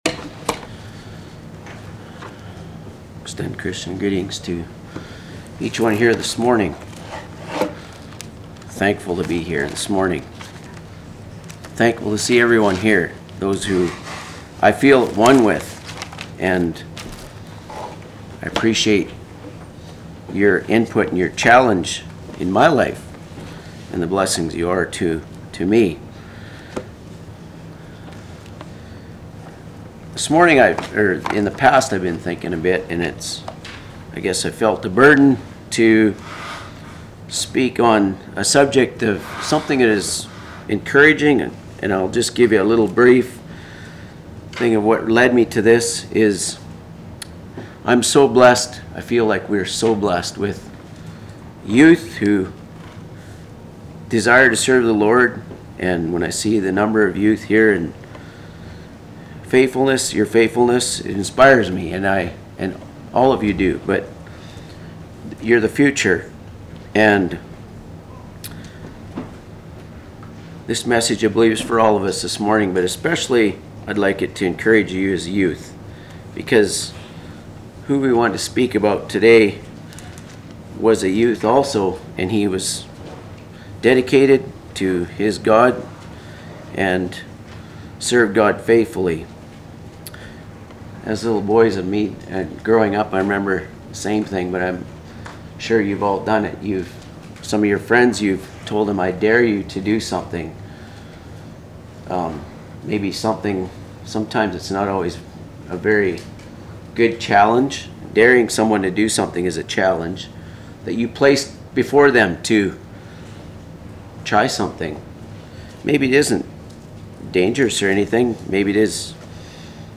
Sermons
Altoona | Bible Conference 2014